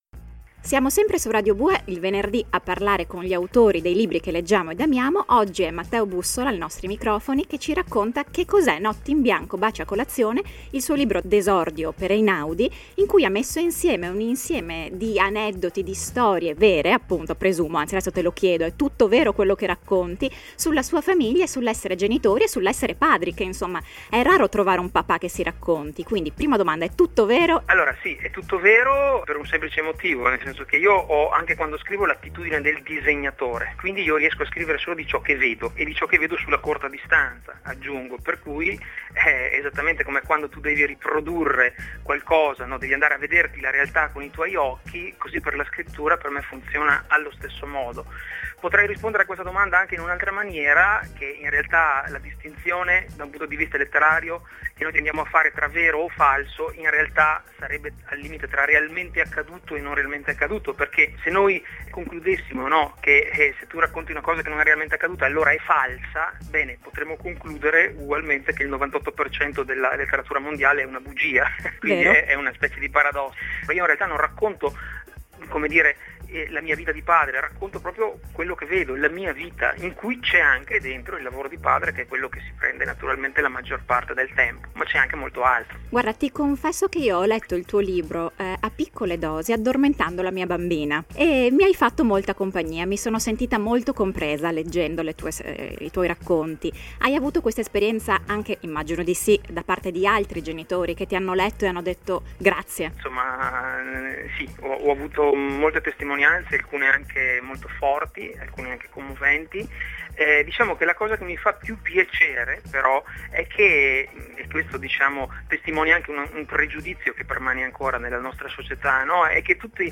Ascolta la seconda parte dell’intervista (o scaricala qui) e prova a scoprire di cosa si tratterà, noi un’idea ce l’abbiamo 🙂